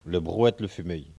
Elle provient de Saint-Gervais.
Locution ( parler, expression, langue,... )